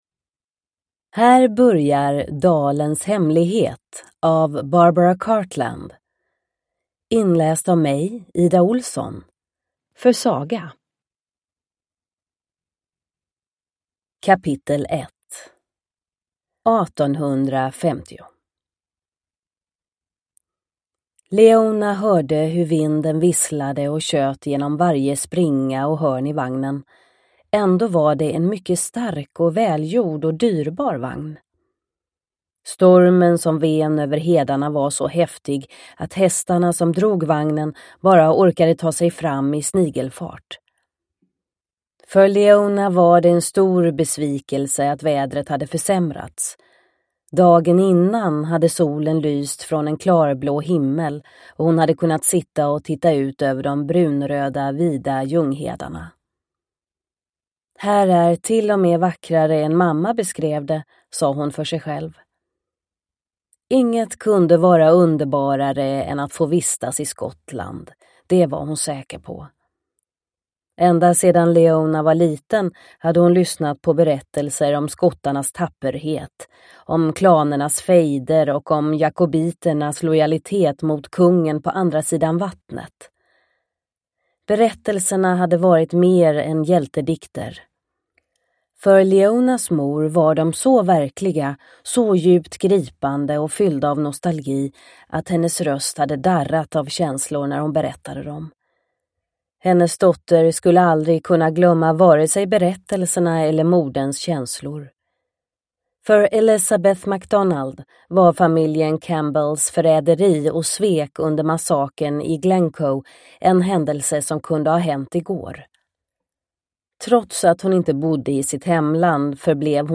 Uppläsare:
Ljudbok